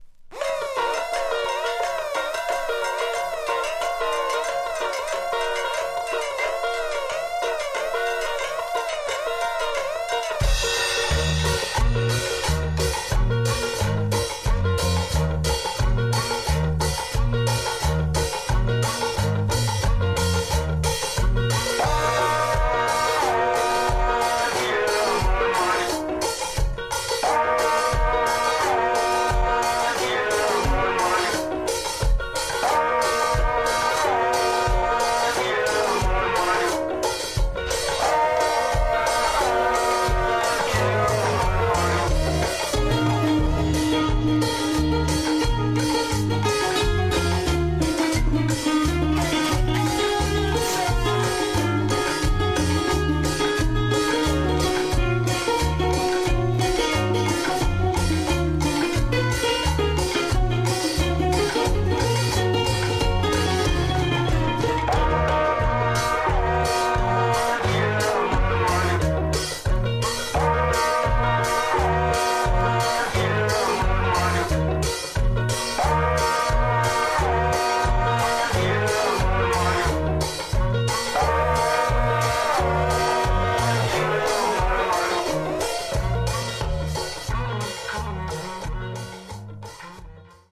Tags: Japan , Cumbia
Deadly cumbia reggae